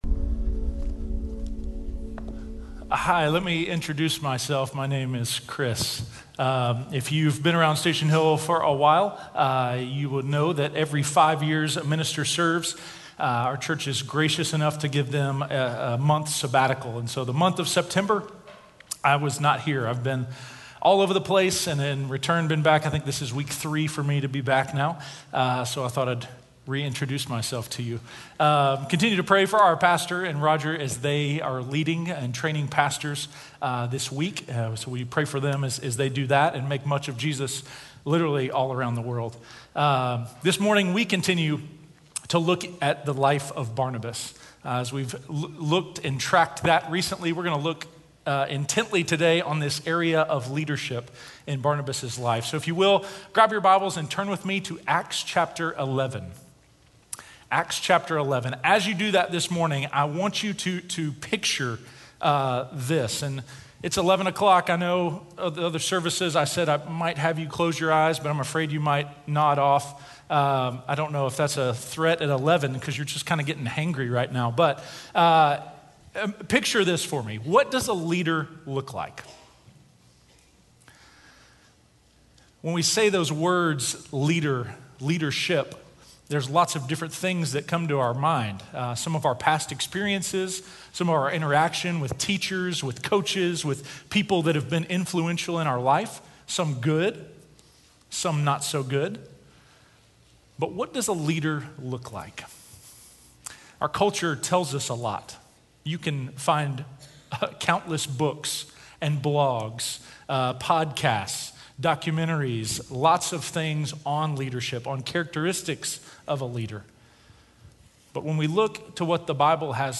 Just Like Barnabas: Leading - Sermon - Station Hill